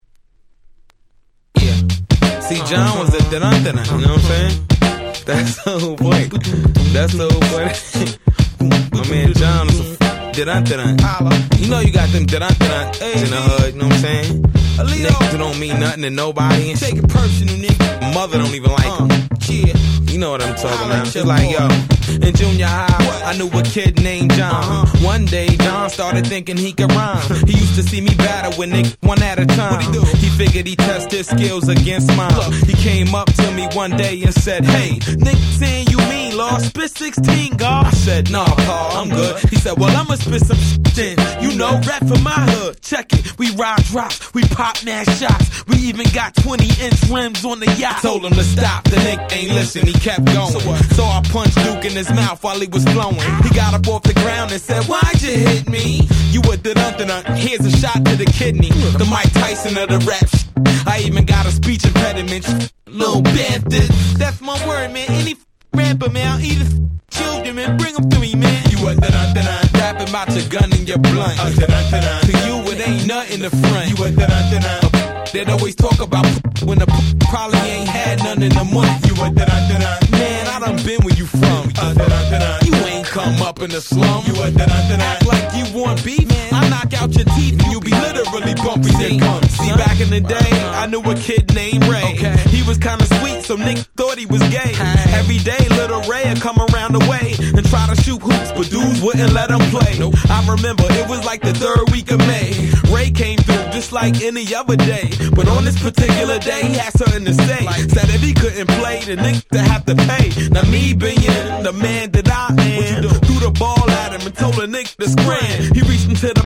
Boom Bap